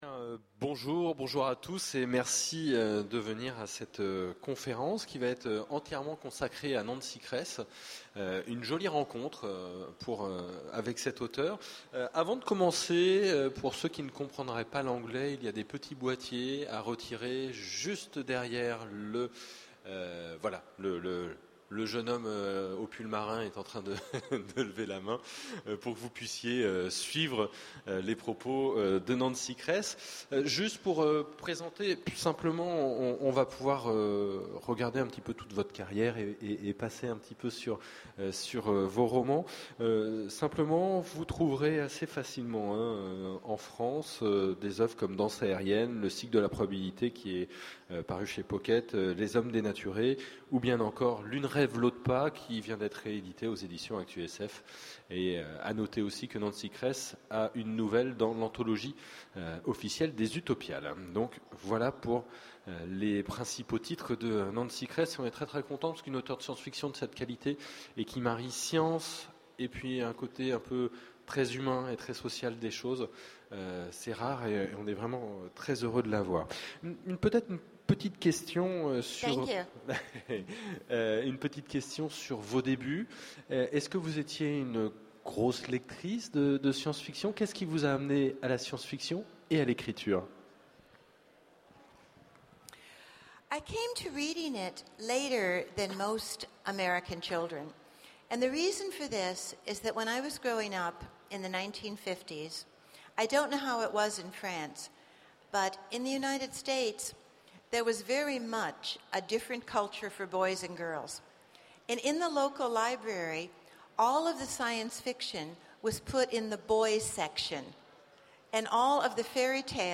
Utopiales 12 : Conférence Rencontre avec Nancy Kress
Aujourd'hui une conférence en 2012 aux Utopiales avec Nancy Kress, l'autrice de L'une rêve, l'autre pas et du roman Les Hommes dénaturés.